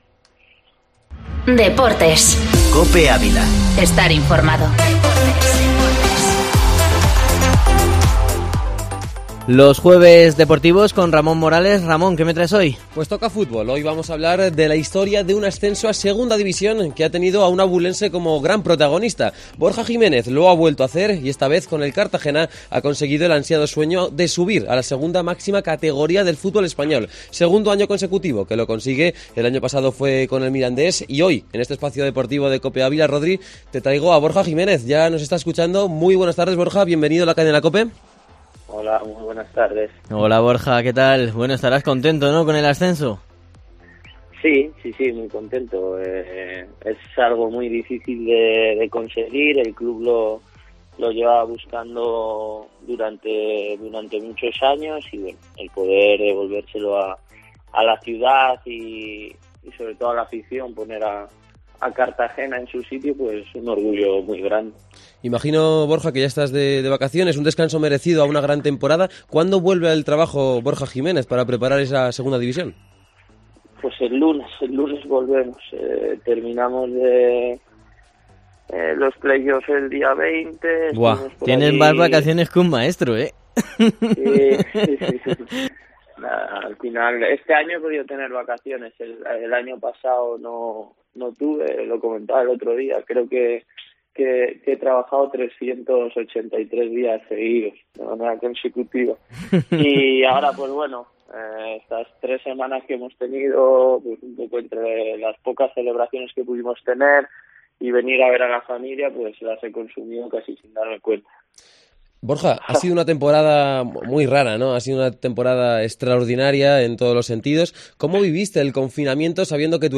Borja Jiménez, recién ascendido a la Segunda División ha hablado en los micrófonos de la Cadena COPE de Ávila del equipo de su ciudad.